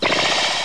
- Tenchi Muyo anime, the teleport sound characters like Ryoko and Azaka and Kamidake make.
teleport.wav